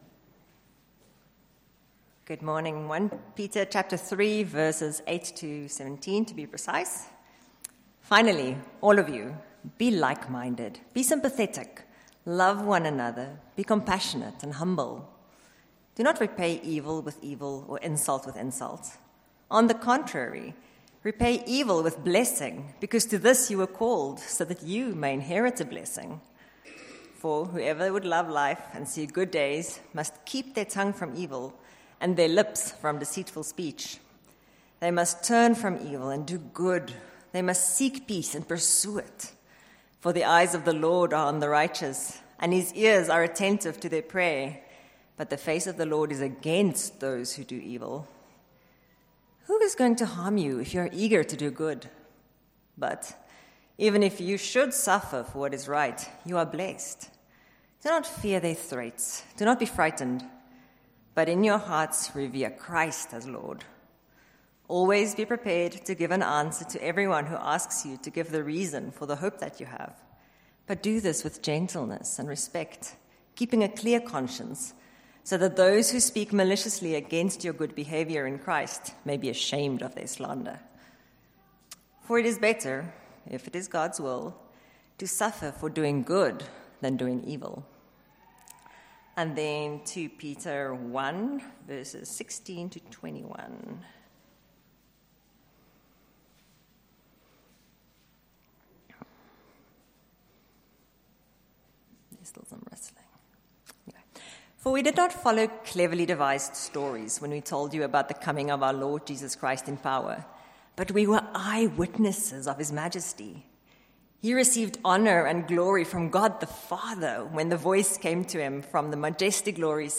16-21 Service Type: Morning Service The battle will be tough